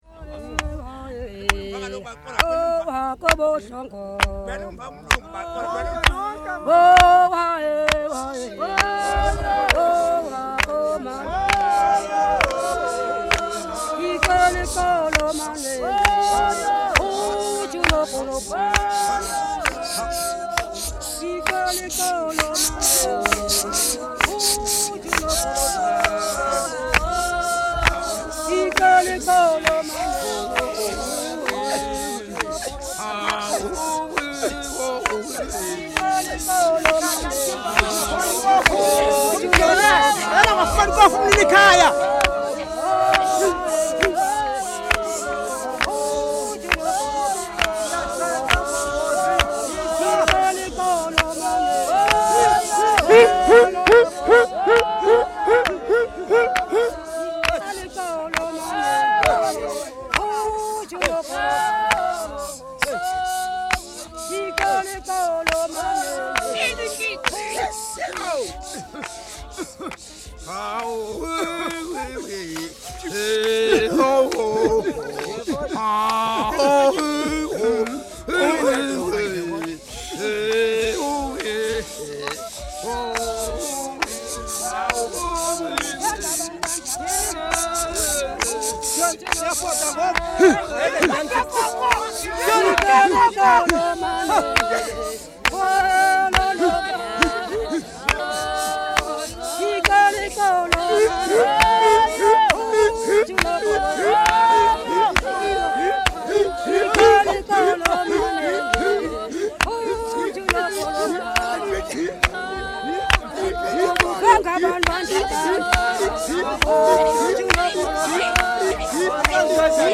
Xhosa men and women
Folk music--South Africa
Field recordings
This song was recorded by moonlight by a very gay and picturesquely dressed group of Xhosa people, young and old men and women.
During the course of this song the owner of the nearest house shouted out: "Should not the owner of this place be paid?"
Song for Mhlahlo dance, with clapping.
96000Hz 24Bit Stereo